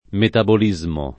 [ metabol &@ mo ]